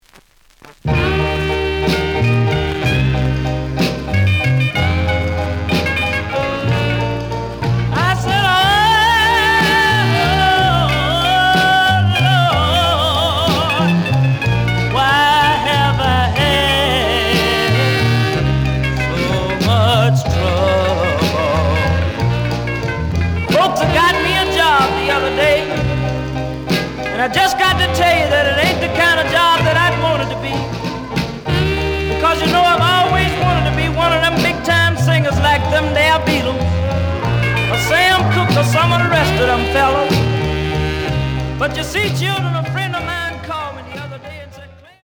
The audio sample is recorded from the actual item.
●Genre: Soul, 60's Soul
Looks good, but slight noise on beginning of A side.)